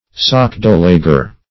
Sockdolager \Sock*dol"a*ger\, n. [A corruption of doxology.]